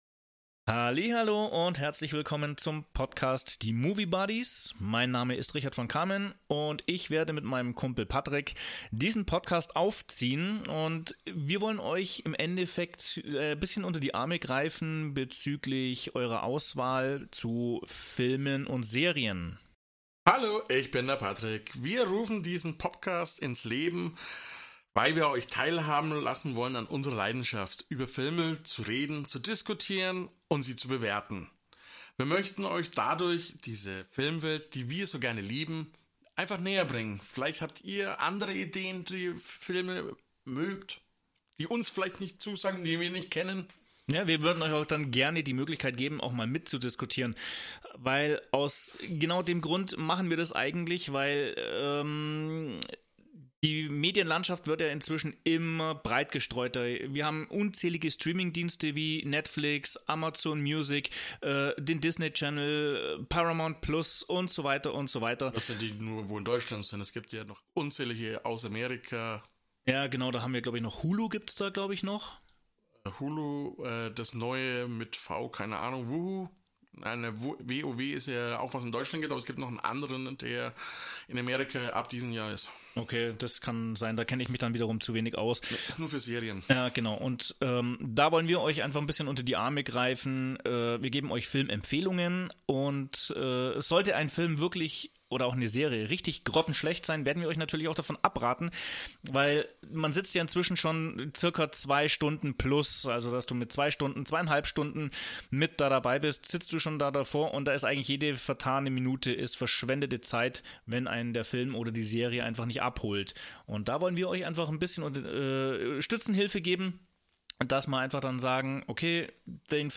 2 Filmfreaks plaudern über Filme und Serien